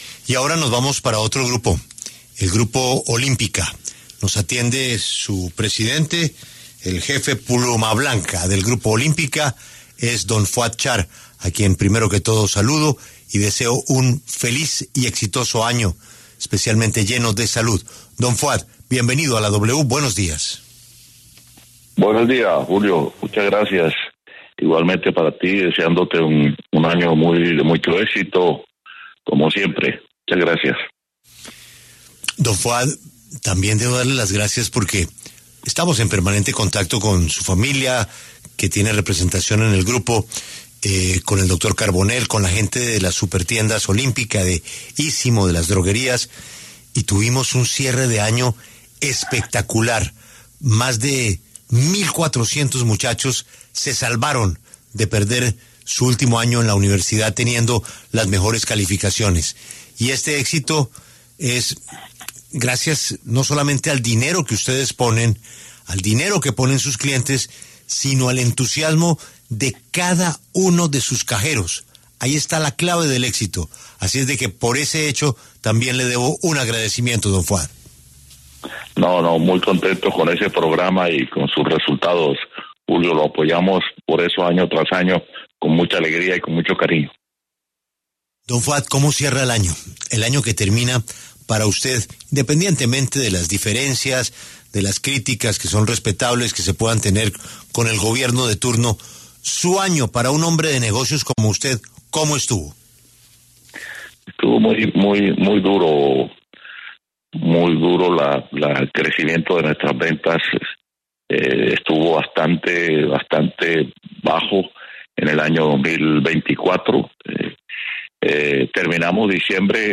Fuad Char, uno de los dueños del Junior de Barranquilla, pasó por los micrófonos de La W, con Julio Sánchez Cristo, para hablar sobre el posible fichaje de James Rodríguez por el conjunto ‘tiburón’, una contratación que daría un ‘golpe sobre la mesa’ en el Fútbol Profesional Colombiano.